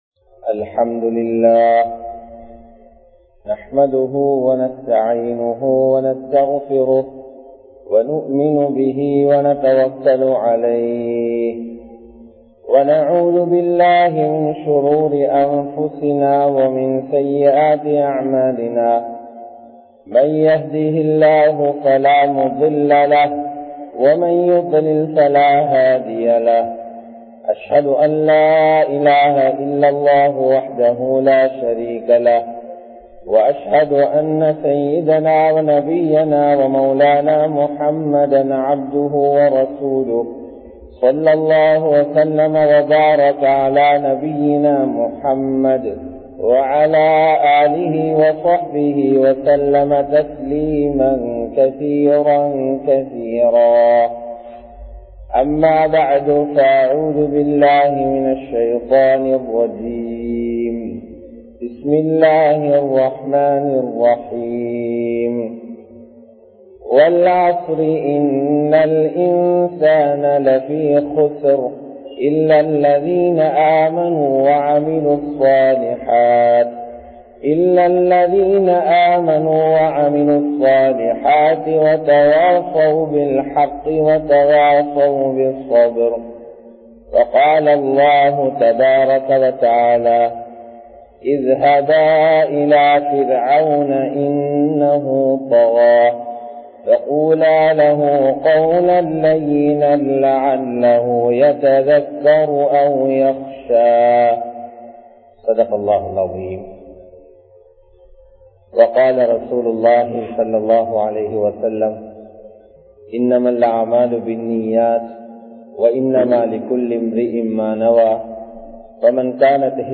தஃவா காலத்தின் தேவை (Dhawa is the Timely Needness) | Audio Bayans | All Ceylon Muslim Youth Community | Addalaichenai
Muhiyaddeen Grand Jumua Masjith